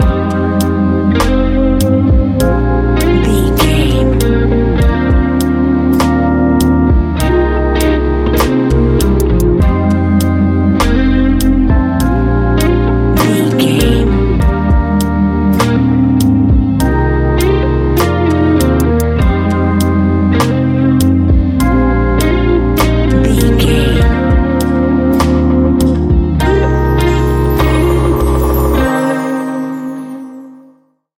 Ionian/Major
C♭
chilled
laid back
Lounge
sparse
new age
chilled electronica
ambient
atmospheric
morphing